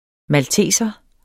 Udtale [ malˈteˀsʌ ]